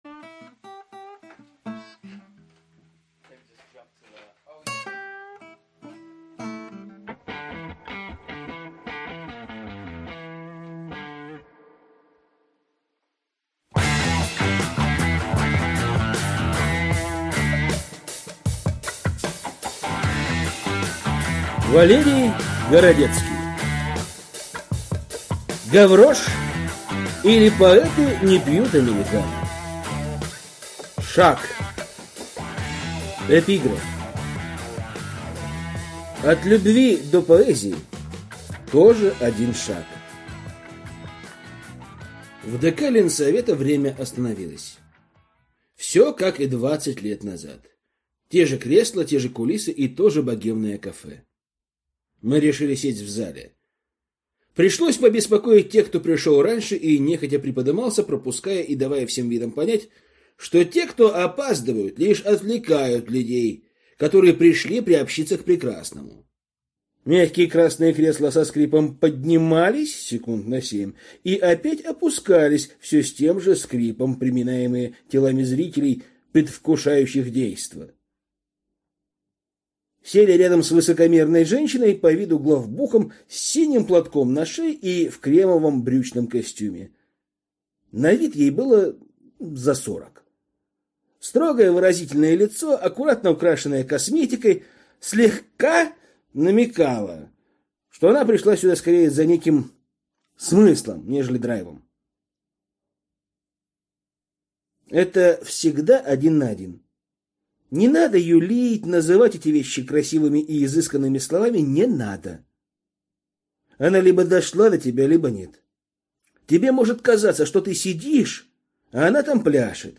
Аудиокнига Гаврош, или Поэты не пьют американо | Библиотека аудиокниг